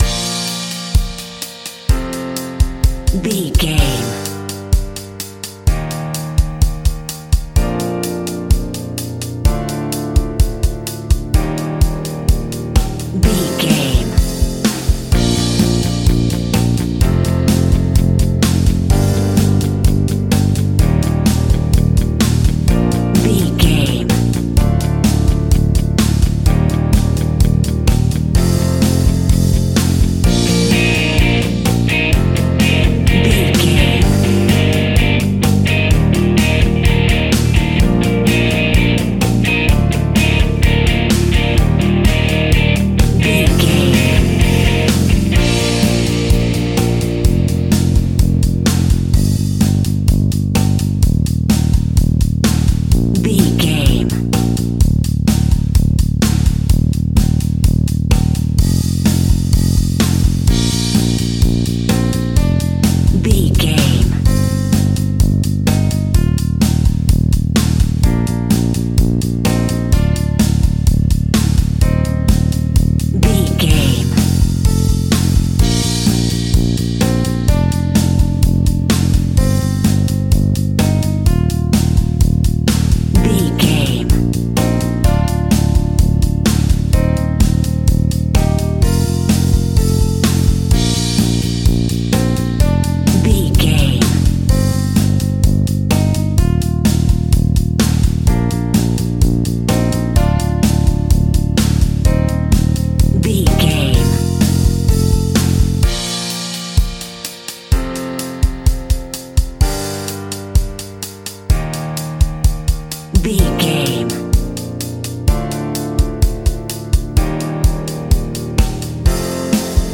Ionian/Major
B♭
indie pop
energetic
uplifting
cheesy
instrumentals
guitars
bass
drums
piano
organ